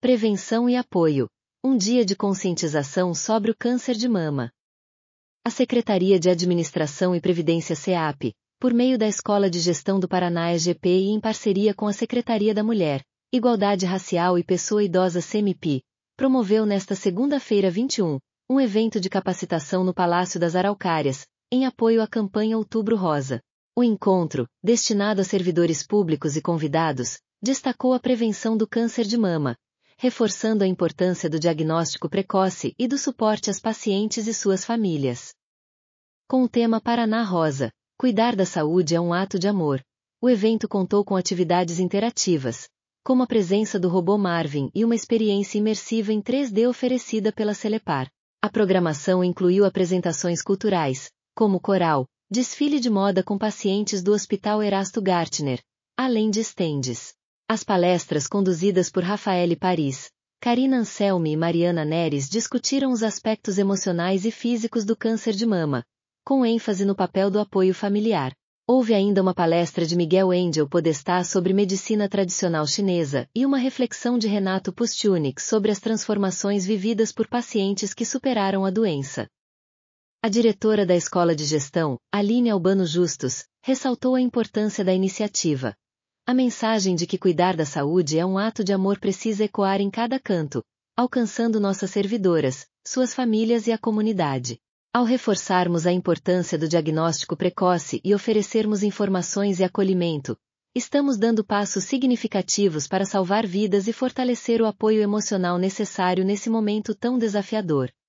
audionoticia_dia_da_mulher.mp3